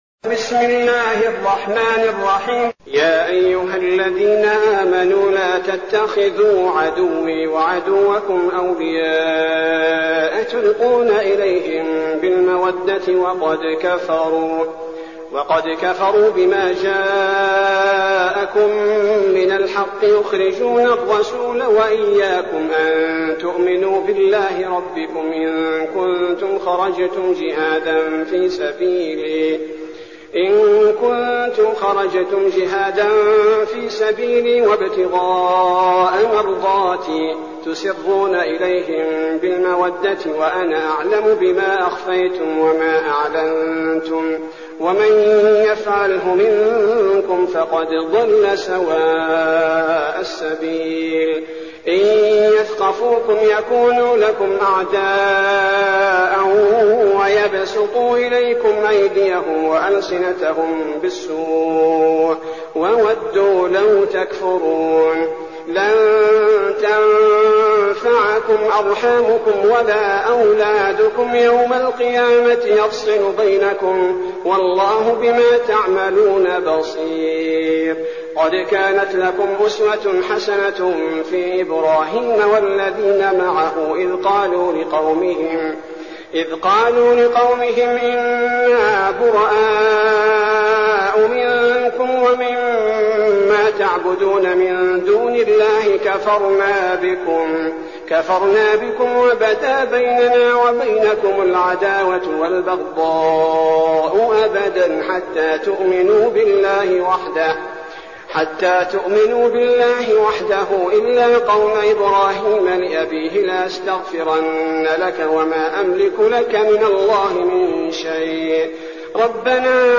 المكان: المسجد النبوي الشيخ: فضيلة الشيخ عبدالباري الثبيتي فضيلة الشيخ عبدالباري الثبيتي الممتحنة